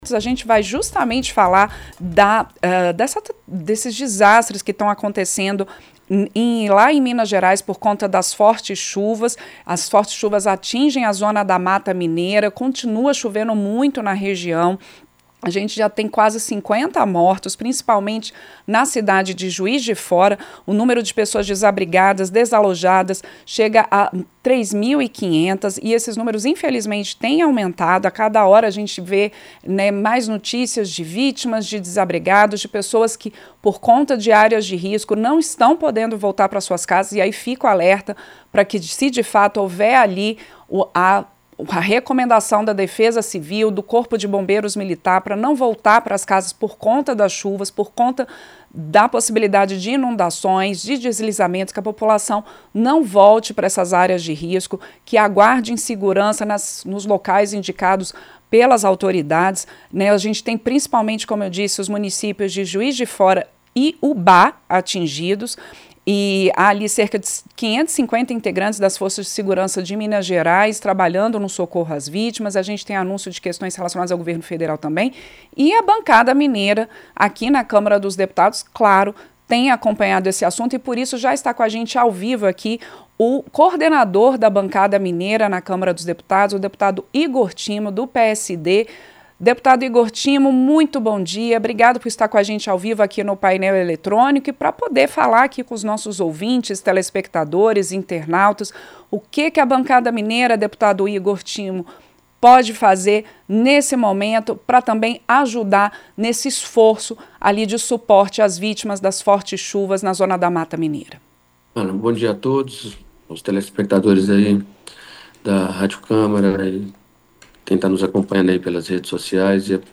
O deputado Igor Timo (PSD-MG), coordenador da bancada mineira na Câmara, disse que o grupo vai se mobilizar para ajudar municípios atingidos pelas fortes chuvas na Zona da Mata, em Minas Gerais. O parlamentar falou ao Painel Eletrônico nesta quinta-feira (26).
Entrevista - Dep. Igor Timo (PSD-MG)